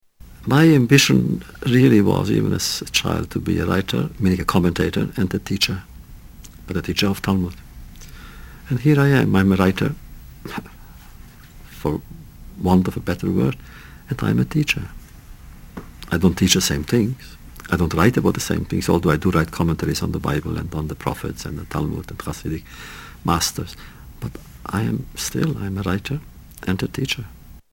Tags: Historical Elie Wiesel Interview Clips Nobel Prize for Peace Writer Holocaust